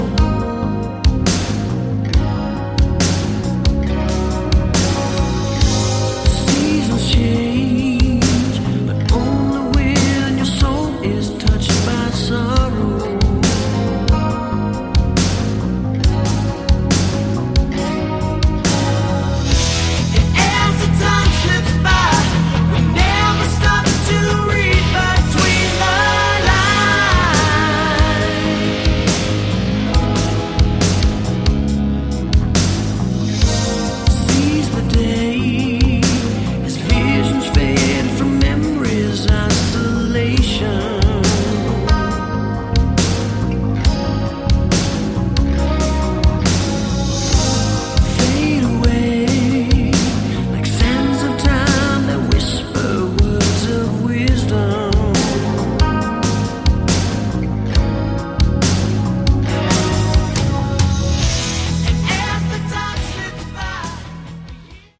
Category: Melodic Metal
guitar and lead vocals
guitar, keyboards and vocals
drums and vocals
bass and vocals